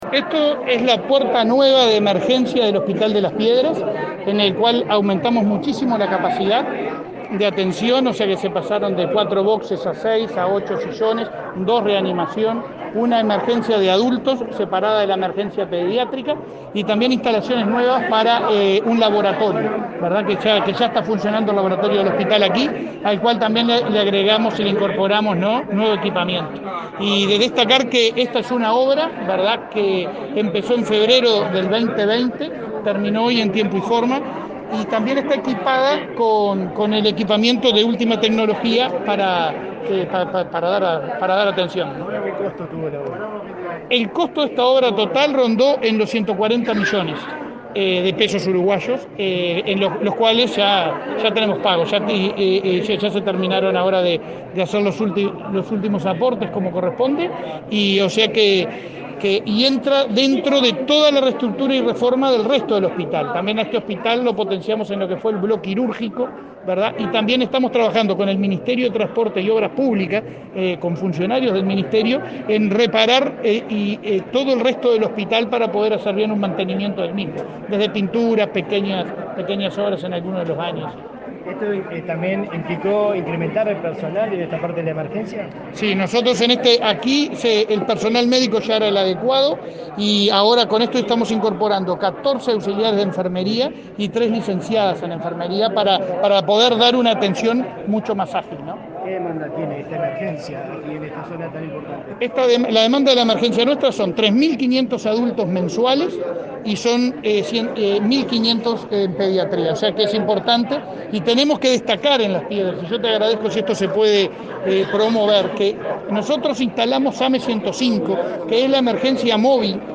Declaraciones del presidente de ASSE, Leonardo Cipriani, a la prensa